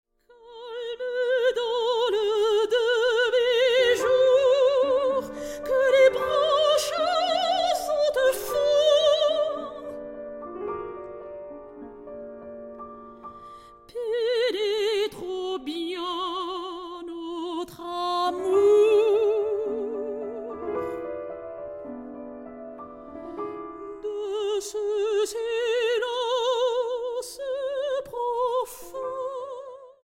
para voz y piano.